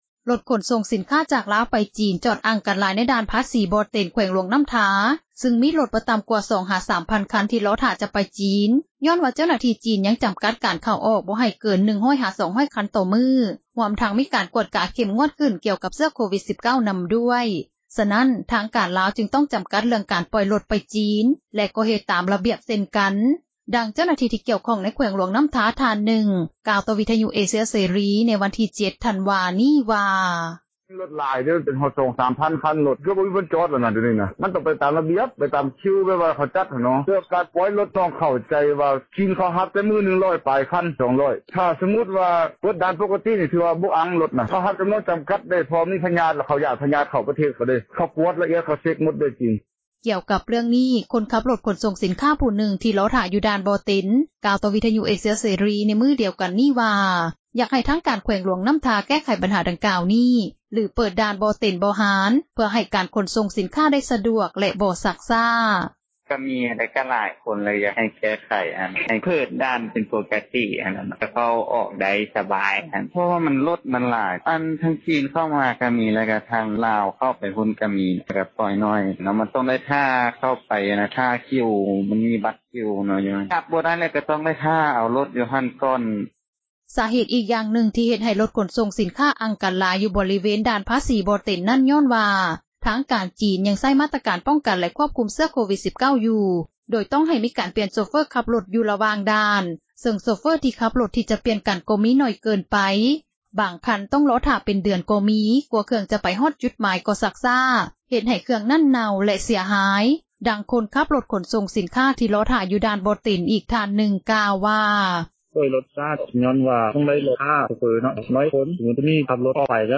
ກ່ຽວກັບເຣື່ອງນີ້ ຄົນຂັບຣົຖຂົນສົ່ງສິນຄ້າຜູ້ນຶ່ງ ທີ່ລໍໍຖ້າຢູ່ດ່ານບໍ່ເຕັນ ກ່າວຕໍ່ວິທຍຸເອເຊັຽເສຣີ ໃນມື້ດຽວກັນນີ້ວ່າ ຢາກໃຫ້ທາງການແຂວງຫຼວງນໍ້າທາ ແກ້ໄຂບັນຫາດັ່ງກ່າວນີ້ ຫຼືເປີດດ່ານບໍ່ເຕັນ-ບໍ່ຫານ ເພື່ອໃຫ້ການຂົນສົ່ງສິນຄ້າ ໄດ້ສະດວກ ແລະບໍ່ຊັກຊ້າ: